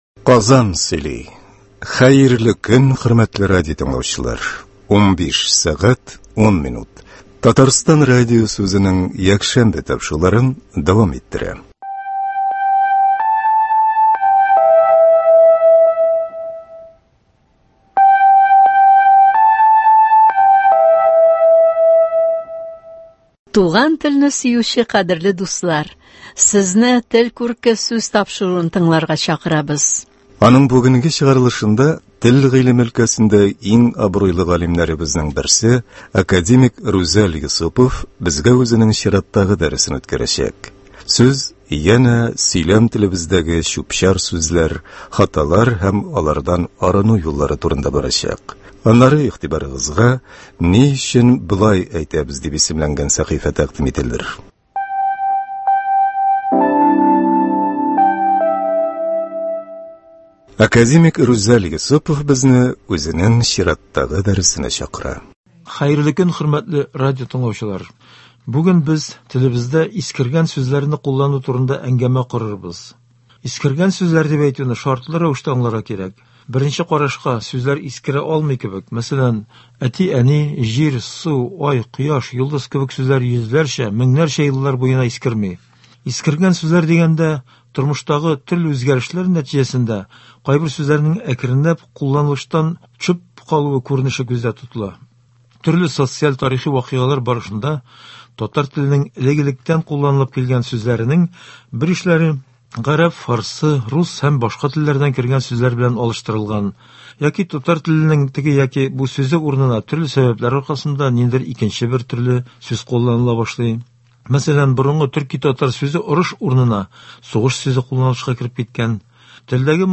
Һәр төбәкнең үз диалекты, үзенчәлекләре бар. Бу тапшыруда күренекле галимнәр, язучылар халкыбызны дөрес сөйләшү, дөрес язу серләренә өйрәтә.